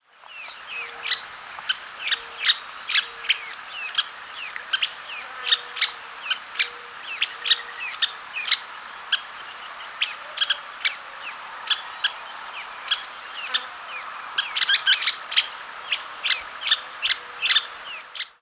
Western Kingbird Tyrannus verticalis
Flight call description A loud, abrupt, squeaky "kip" may occasionally be used as a flight call.
Diurnal calling sequences:
"Kip" calls and twitter from perched bird with Mourning Dove in the background.